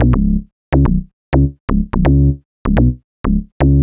cch_bass_loop_weird_125_D.wav